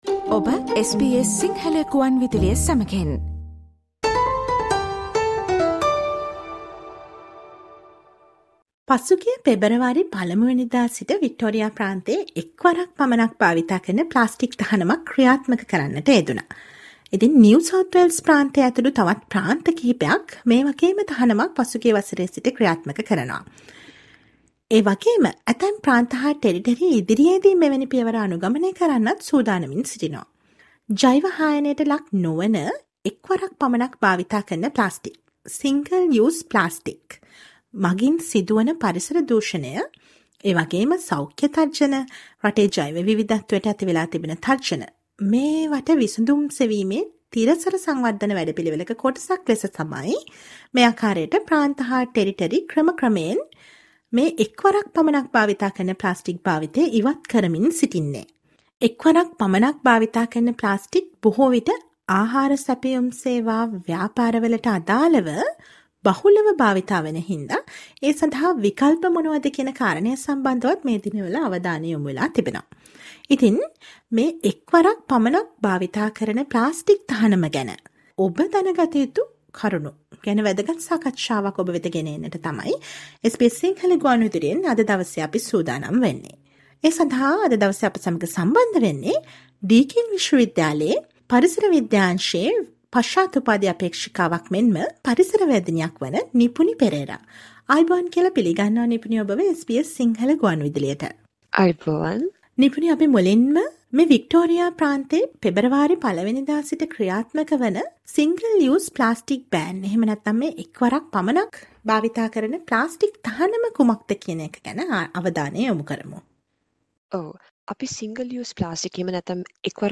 ඔස්ට්‍රේලියාවේ ප්‍රාන්ත හා ටෙරිටරි පුරා ක්‍රමයෙන් ක්‍රියාත්මක වන එක්වරක් පමණක් භාවිතා කරන ප්ලාස්ටික් තහනමට අනුකූලව කටයුතු කිරීම කොයි තරම් වැදගත්ද ඒ ගැන ඔබ දන ගත යුතුම කරුණු මොනවාද දැනුවත් වෙන්න සවන් දෙන්න මේ ගුවන් විදුලි විශේෂාංගයට